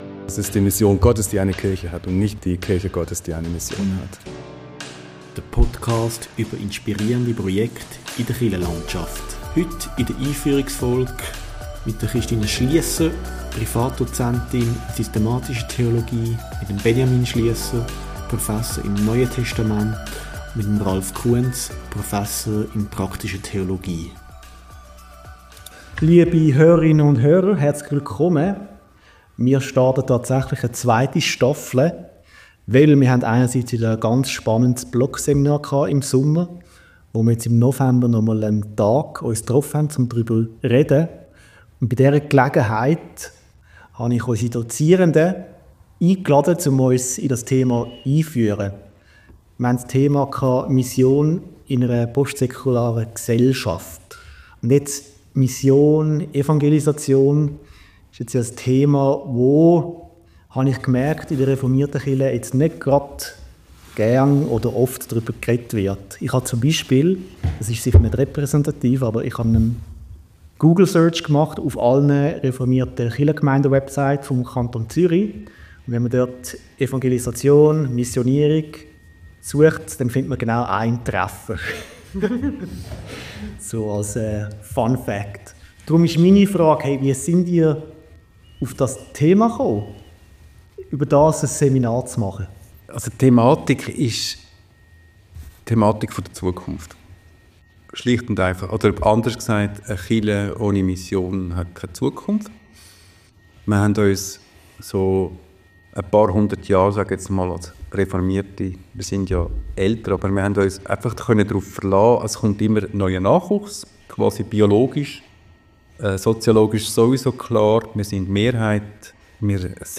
Beschreibung vor 1 Jahr Mission ist nicht nur ein historisches Konzept, sondern die zentrale Existenzfrage der Kirche. In diesem Podcast sprechen Expert:innen aus Theologie und Praxis über die Herausforderungen und Chancen der Mission in einer Gesellschaft, in der Kirche nicht mehr selbstverständlich ist.